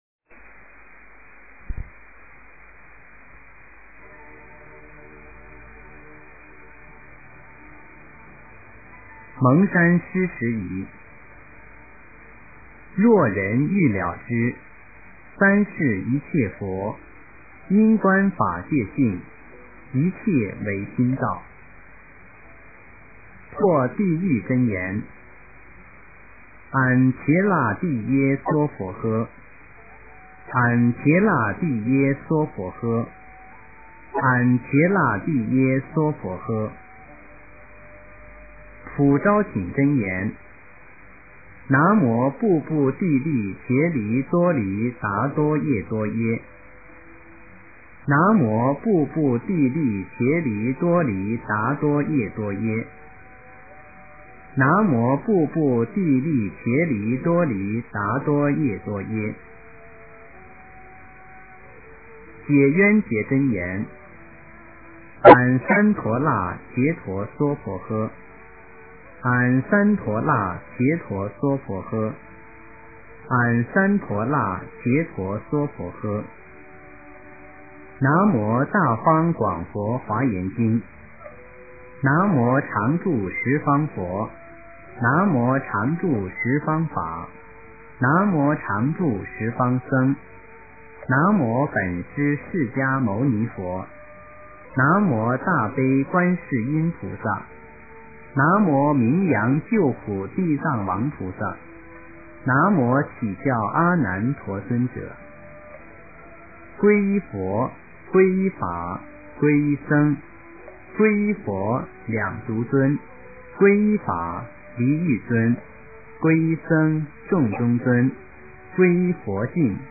暮时课诵读 蒙山施食仪 诵经 暮时课诵读 蒙山施食仪--佚名 点我： 标签: 佛音 诵经 佛教音乐 返回列表 上一篇： 大般若波罗蜜多经第458卷 下一篇： 法华经方便品 相关文章 千手观音 千手观音--任妙音...